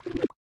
Minecraft Version Minecraft Version 1.21.5 Latest Release | Latest Snapshot 1.21.5 / assets / minecraft / sounds / block / sculk / charge5.ogg Compare With Compare With Latest Release | Latest Snapshot
charge5.ogg